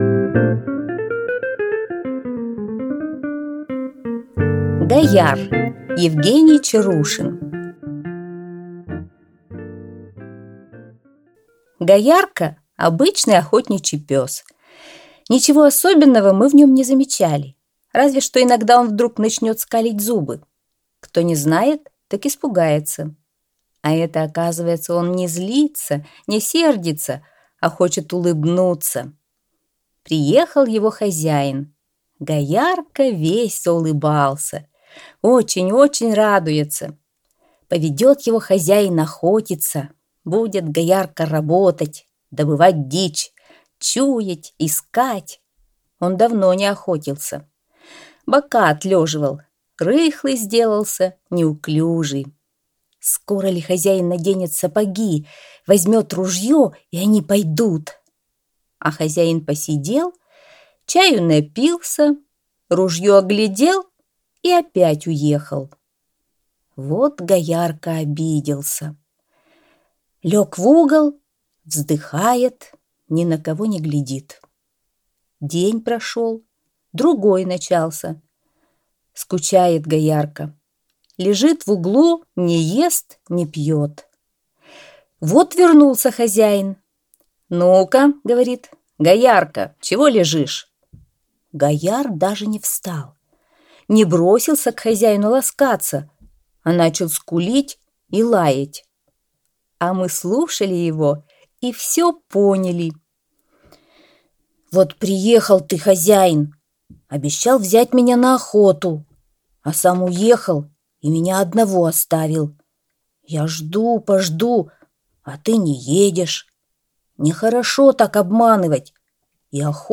Аудиорассказ «Гаяр»